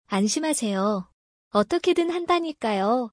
アンシマセヨ. オットケドゥン ハンダニカヨ